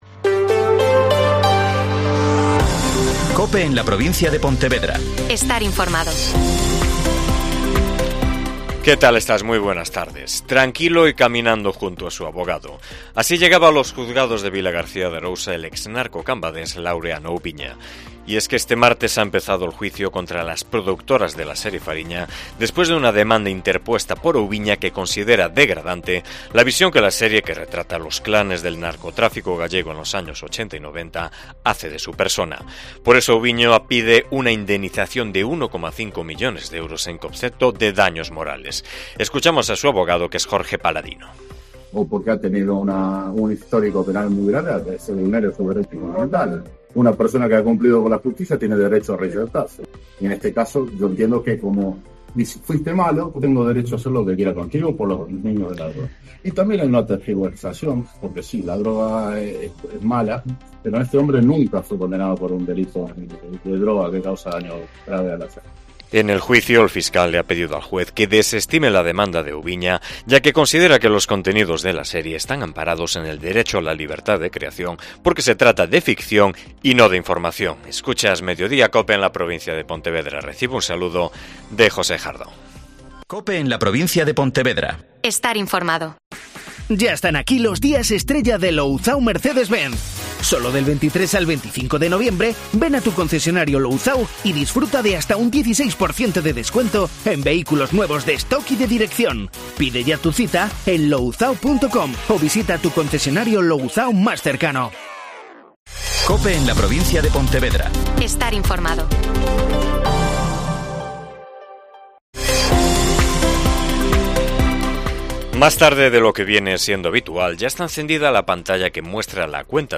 AUDIO: Informativo provincial